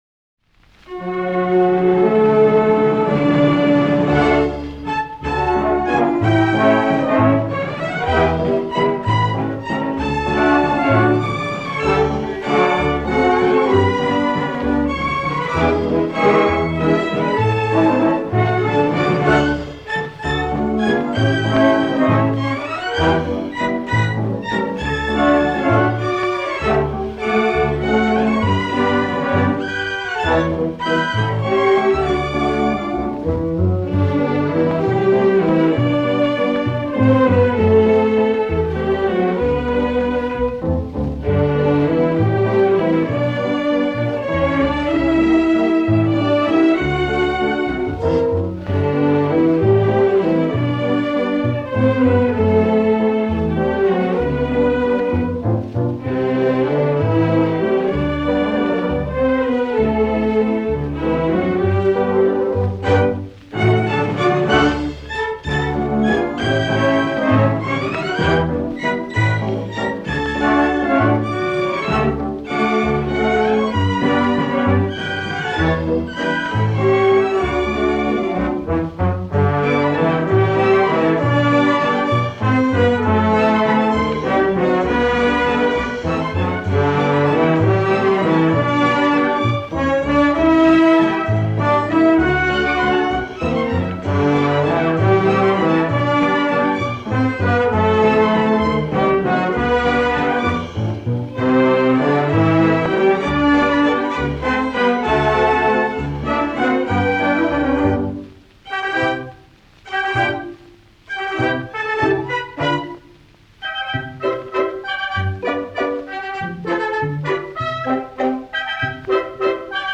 7. [instrumental].
Vals y polca.
78 rpm